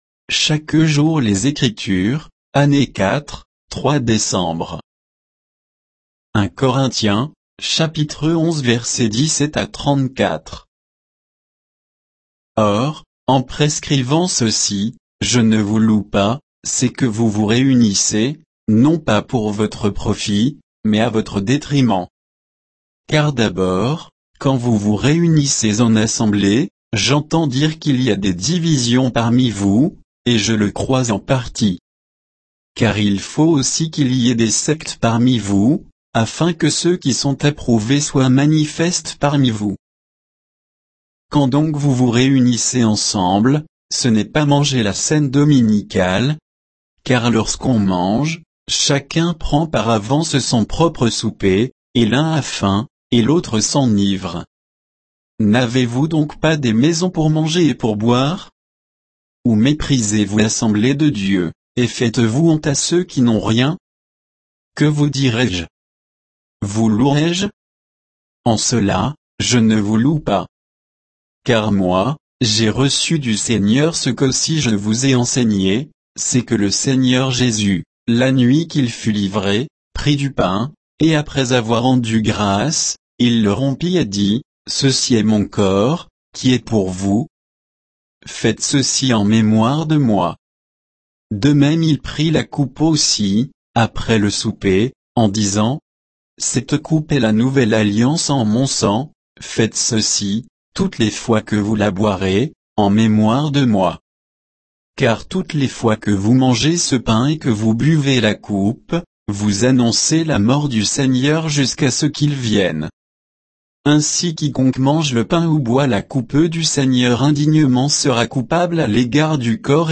Méditation quoditienne de Chaque jour les Écritures sur 1 Corinthiens 11, 17 à 34